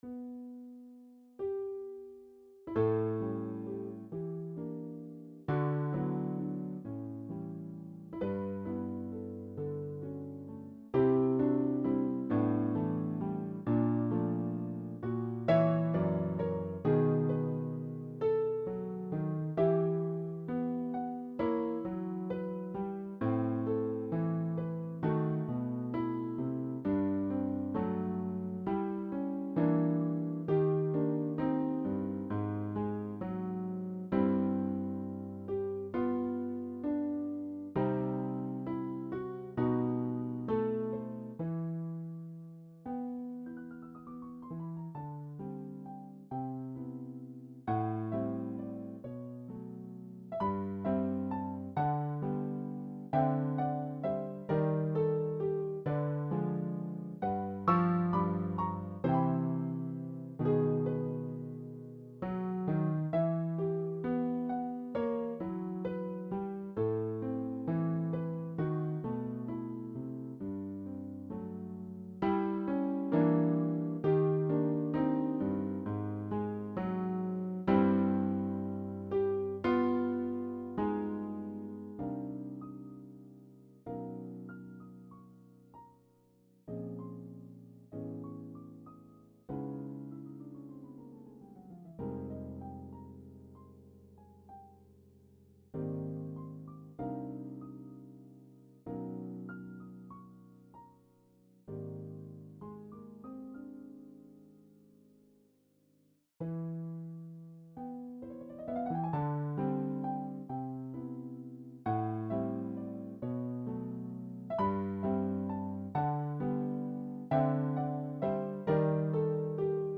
Christmas Carol (Villancico de Navidad) - Piano Music, Solo Keyboard